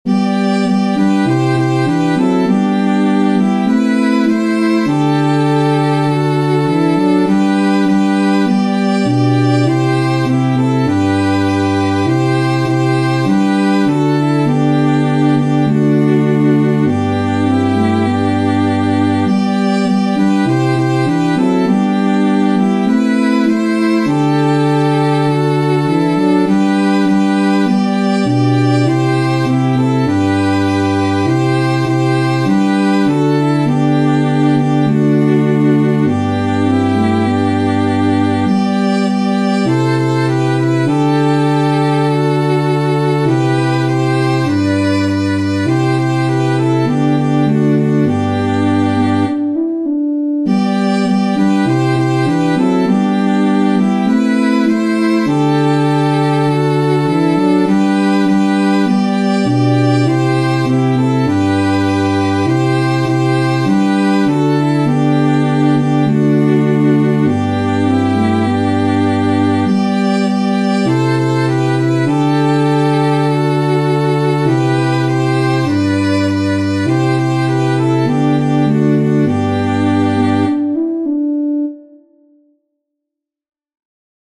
Alléluia, Que dans vos cœurs reigne la Paix Composers : BLAISE-PASCAL KAVULU Catégorie : Chants d’Acclamations.